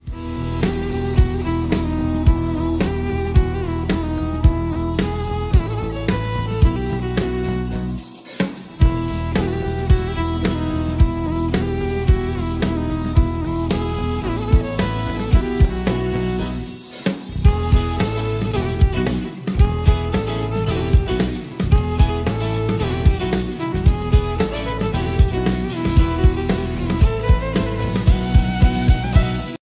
intense energy
BGM ringtone
Categories BGM Ringtones